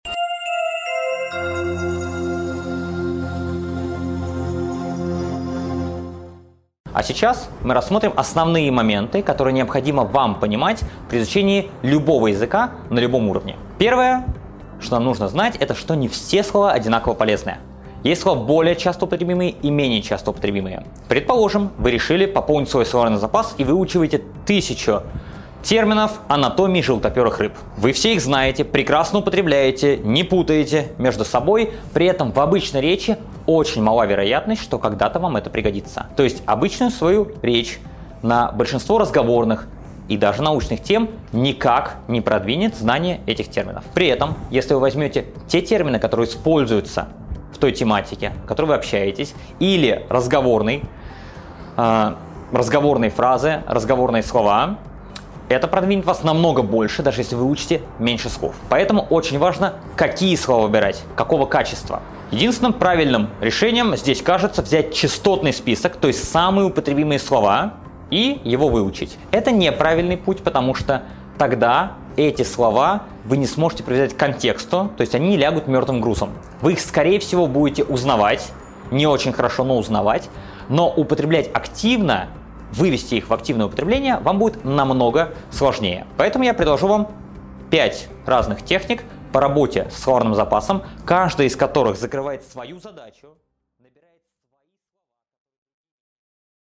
Аудиокнига Как быстро и эффективно пополнить словарный запас английского | Библиотека аудиокниг